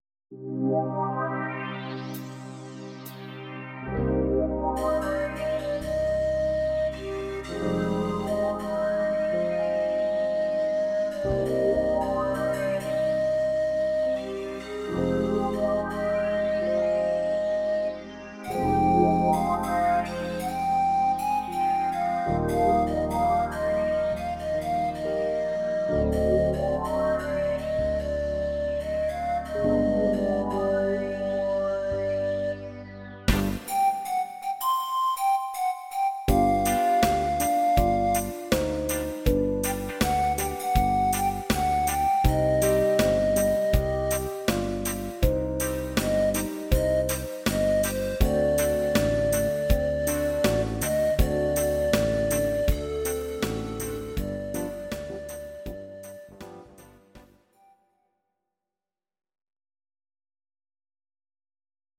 Audio Recordings based on Midi-files
Pop, Musical/Film/TV, 1980s